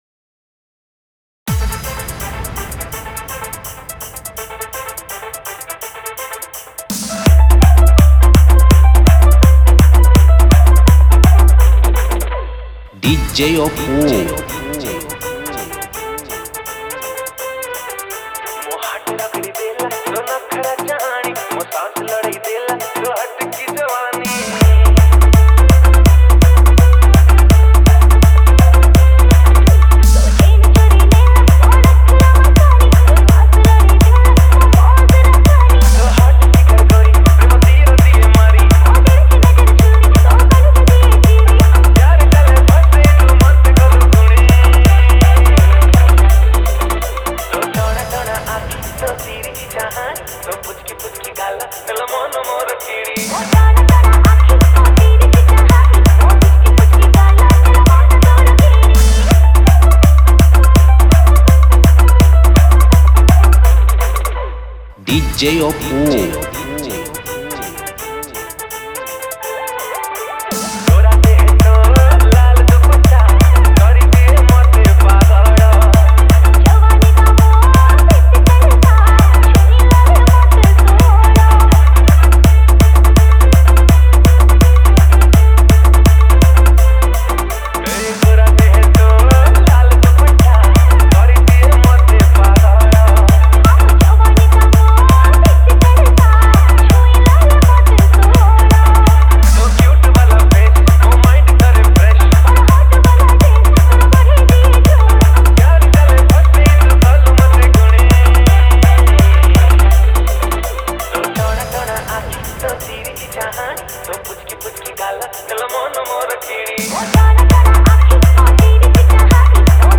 Odia New Power Mix